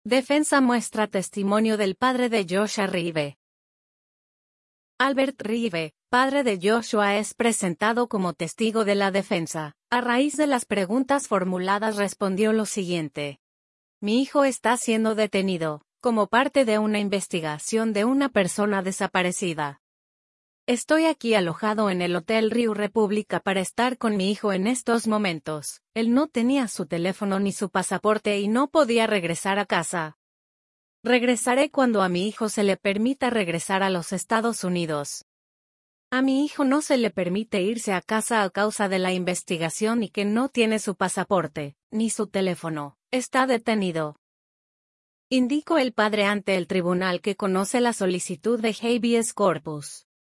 Defensa muestra testimonio